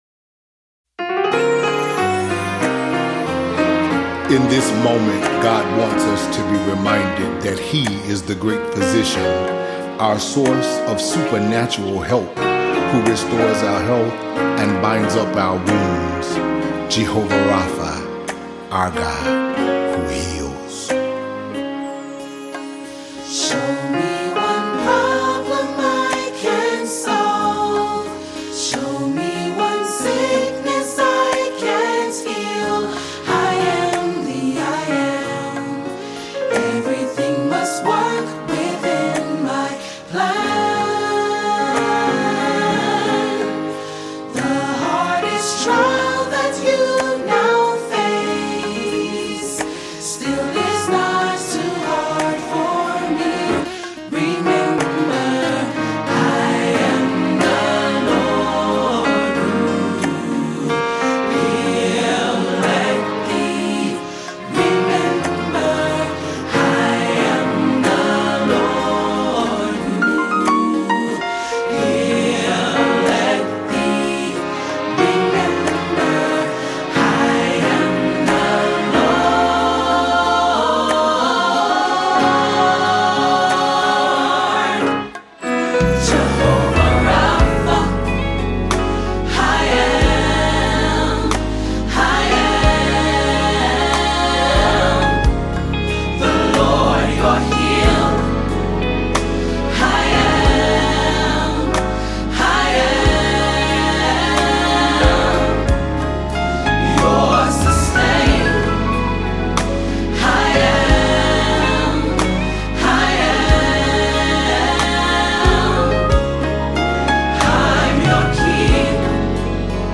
This soothing ballad appears as a part of the EP